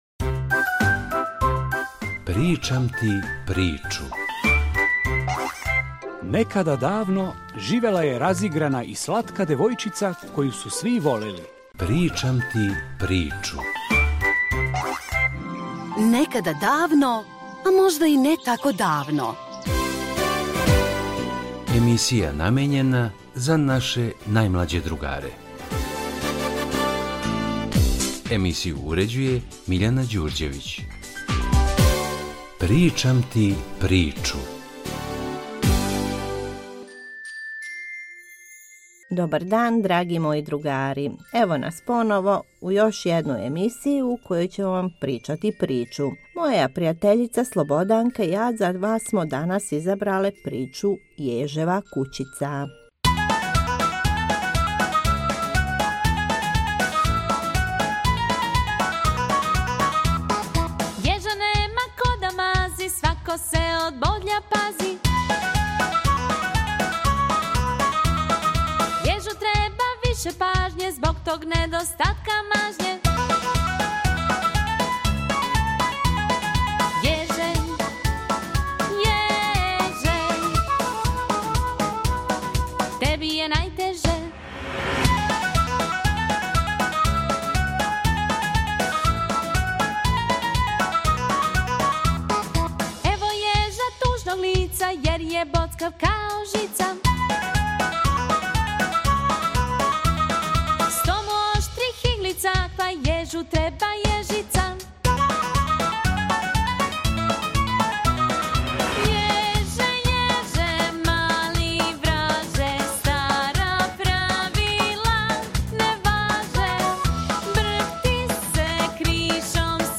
Poslušajte emisiju za djecu – Bajka “Ježeva kućica”